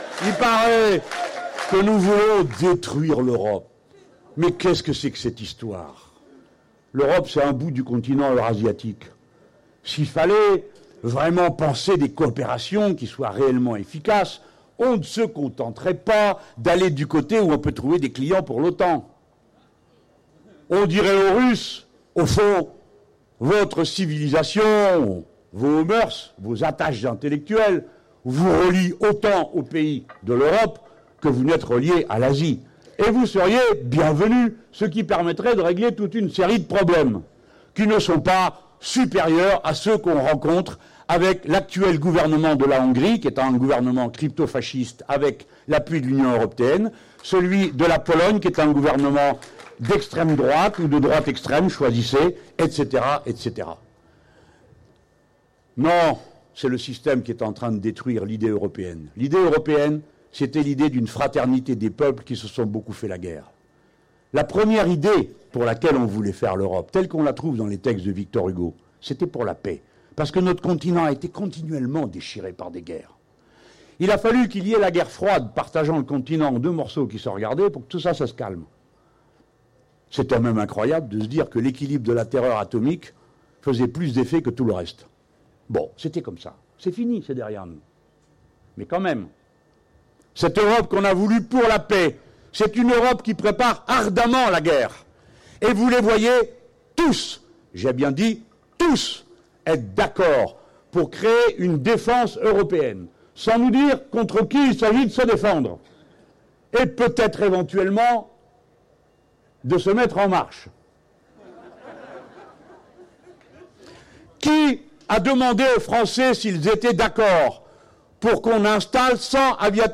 Nous allons inciter les Français à lui mettre une raclée démocratique», lance Jean-Luc Mélenchon devant quelque 2 000 personnes réunies à Marseille dans le cadre de l’université d’été de la France Insoumise.